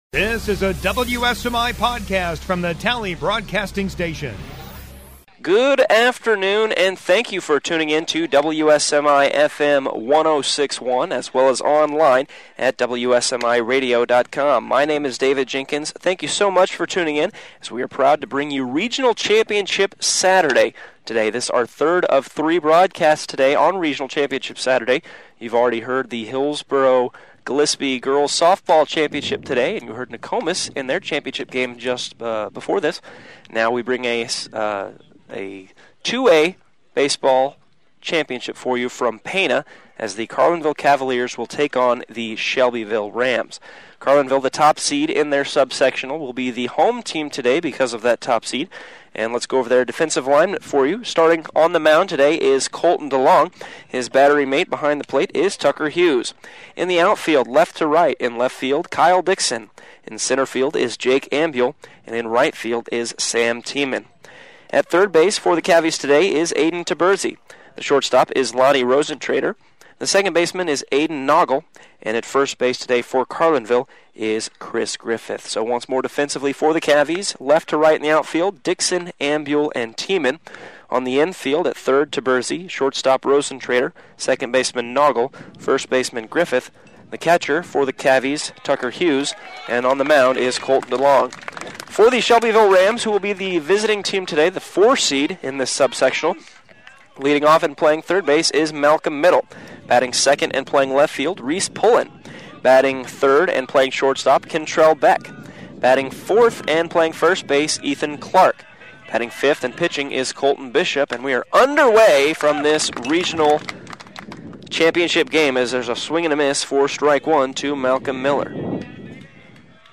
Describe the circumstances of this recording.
05/18/2019 2A Pana Baseball Regional Championship Carlinville Cavaliers vs. Shelbyville Rams